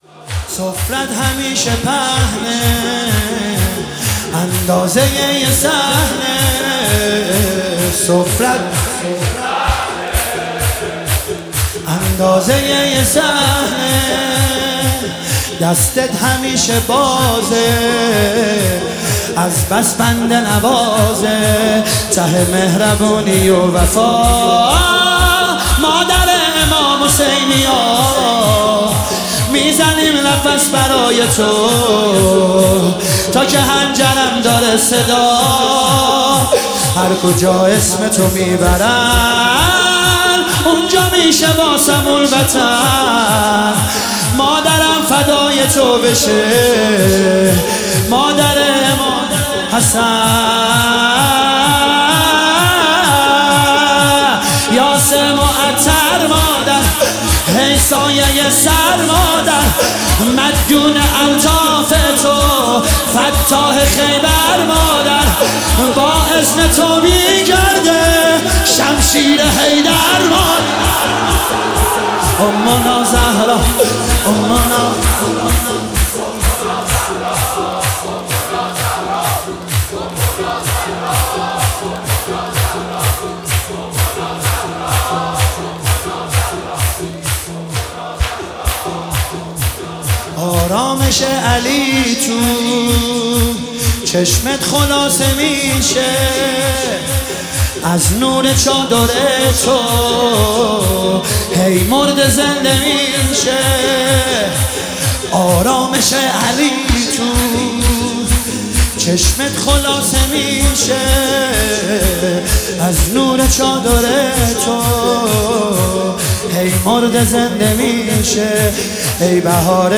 مداحی شور
شور حضرت زهرا